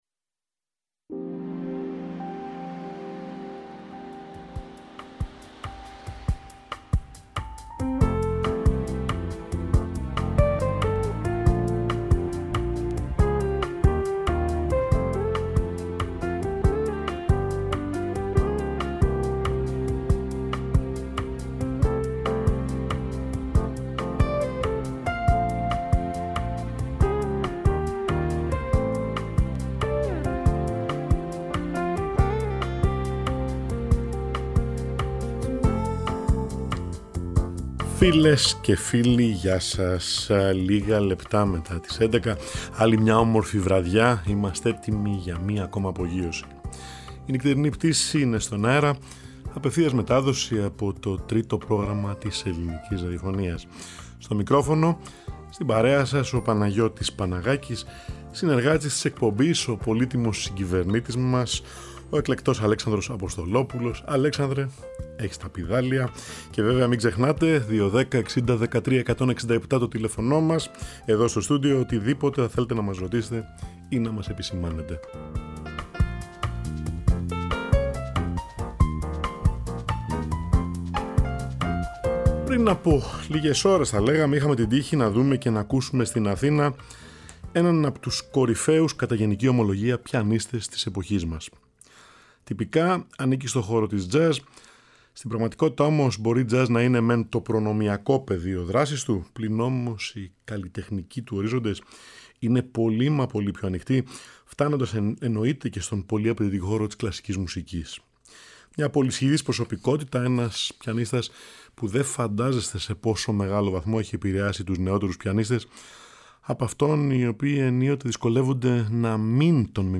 Εργα για Πιανο Μουσικη Jazz